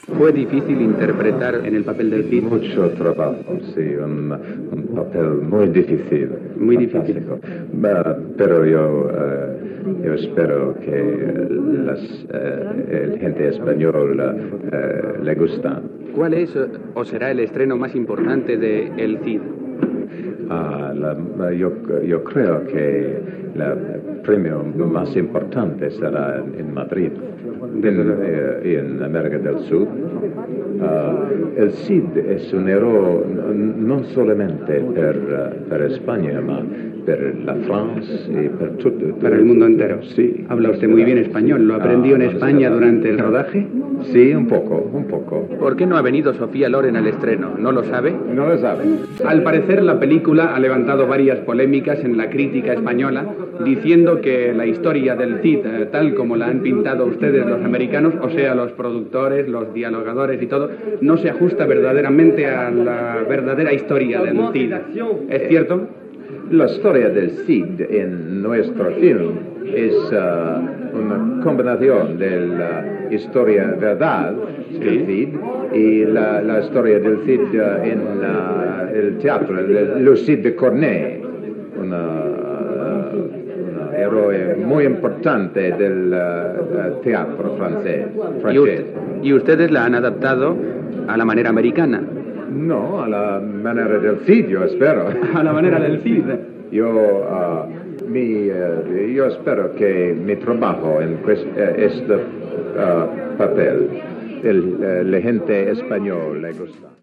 Entrevista a l'actor Charlton Heston, feta el 27 de desembre, en el dia de l'estrena a Madrid de la pel·lícula "El Cid"